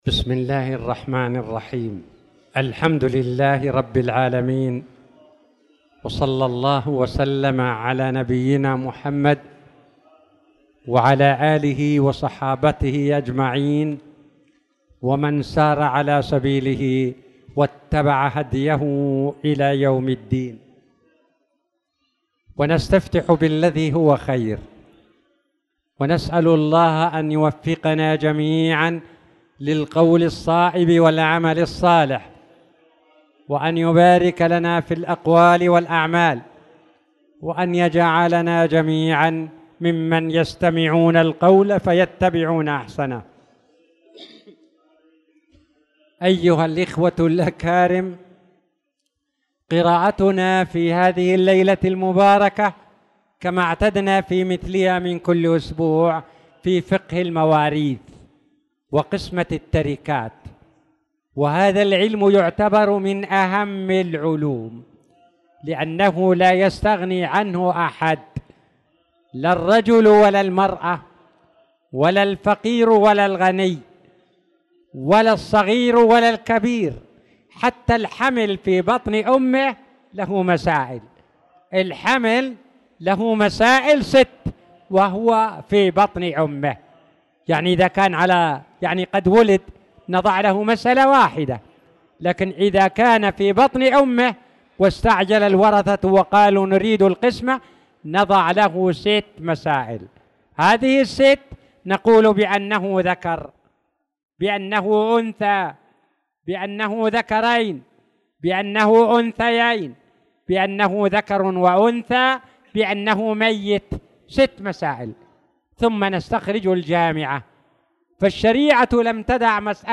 تاريخ النشر ١٣ شوال ١٤٣٧ هـ المكان: المسجد الحرام الشيخ